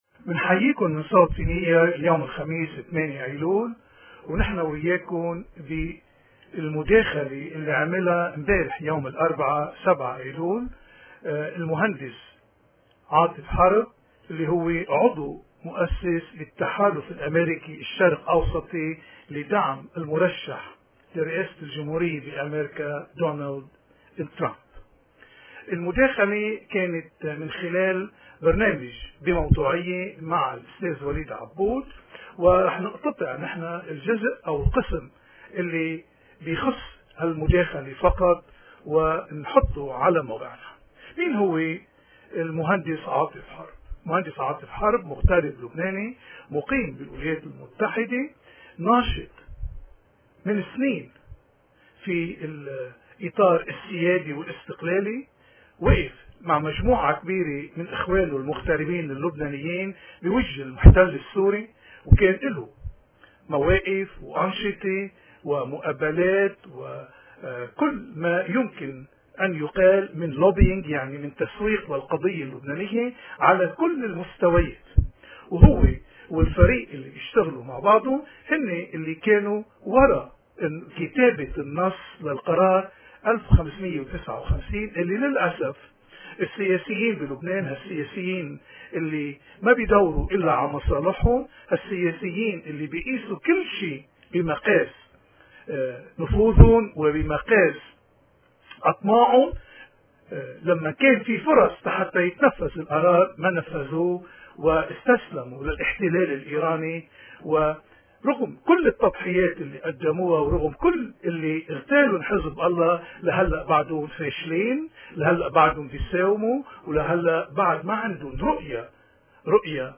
مداخلة